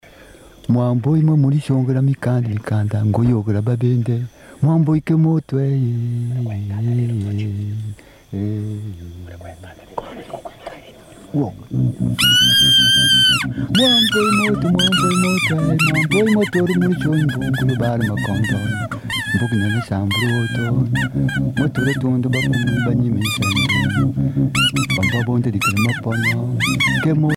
Séquence d'une cérémonie rituelle des masques
Pièce musicale éditée